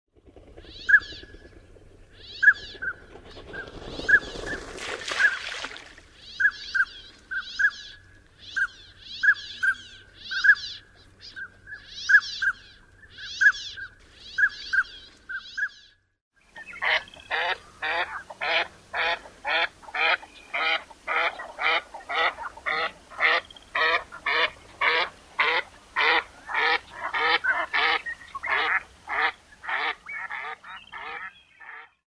Northern Pintail
Voice
The male’s call is a soft, double-toned whistle that sounds like kwee-hee. Females make a low, hoarse quack.
northern-pintail-call.mp3